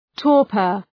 Προφορά
{‘tɔ:rpər}